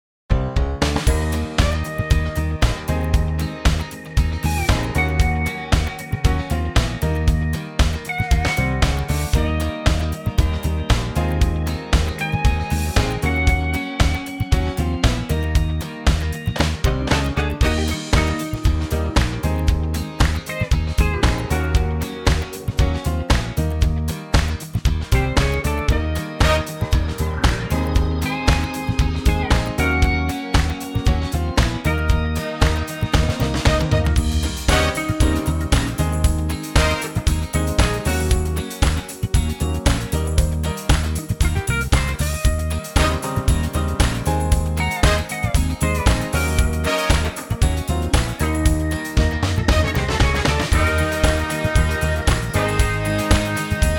Unique Backing Tracks
key - Eb - vocal range - Db to Bb (blues based licks)